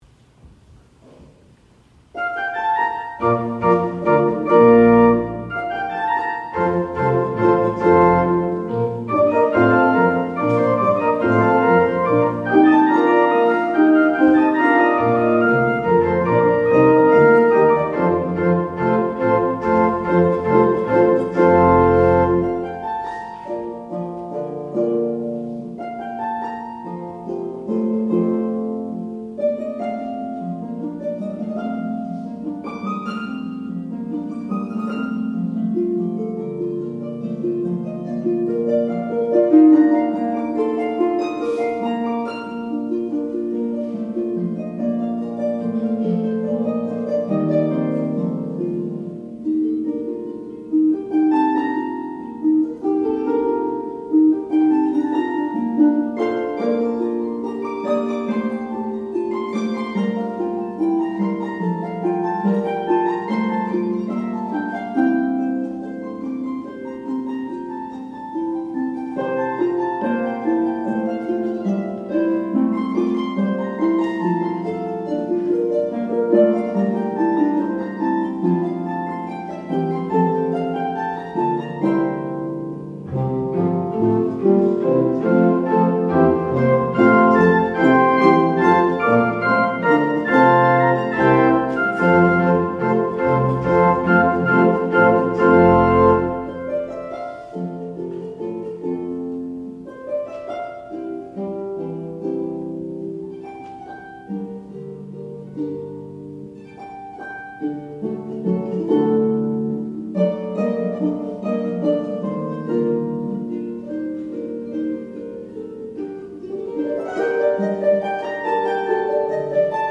Offertory